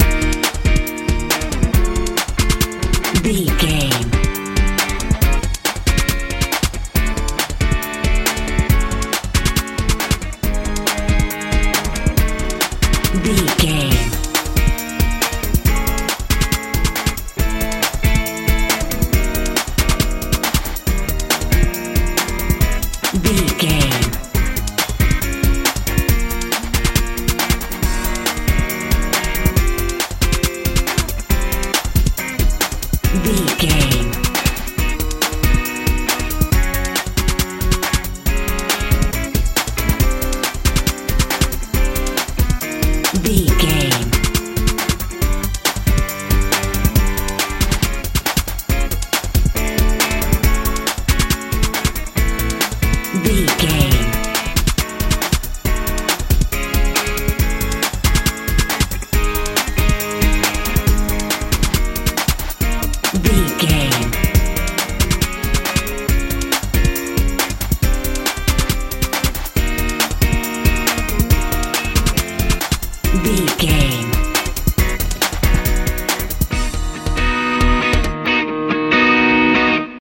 trance feel
Ionian/Major
E♭
groovy
funky
electric guitar
synthesiser
bass guitar
drums
sweet